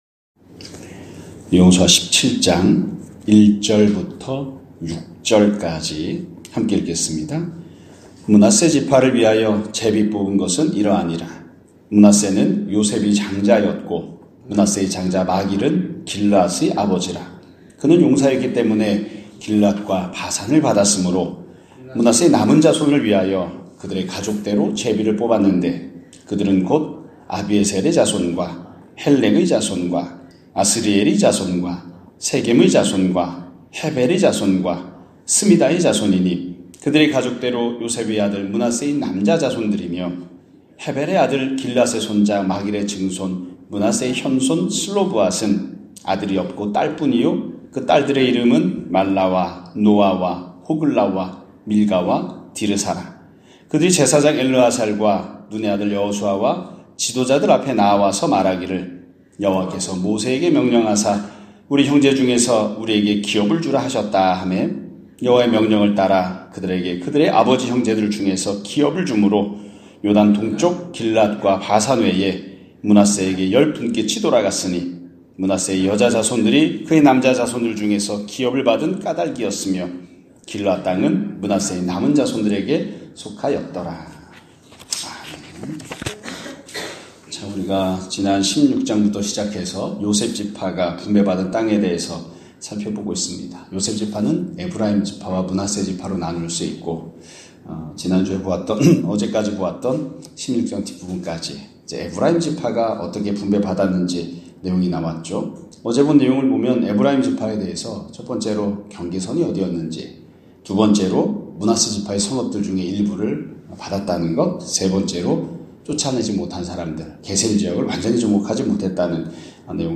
2024년 12월 13일(금요일) <아침예배> 설교입니다.